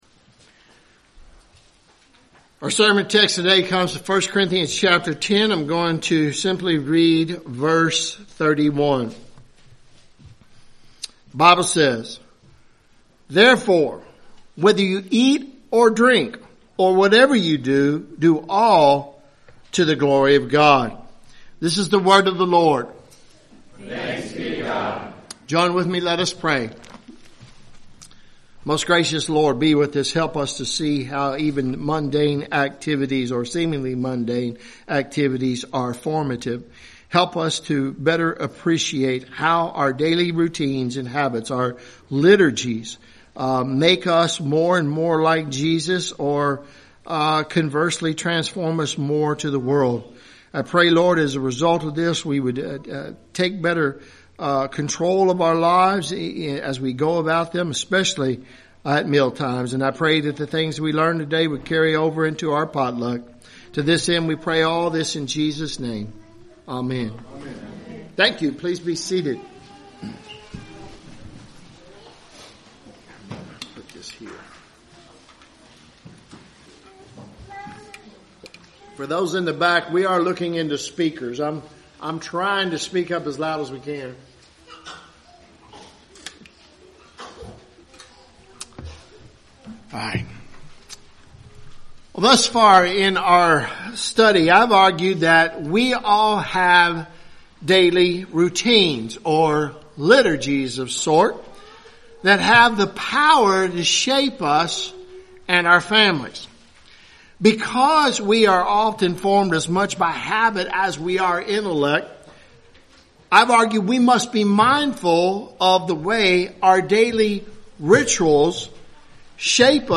Mealtimes – Christ Covenant Presbyterian Church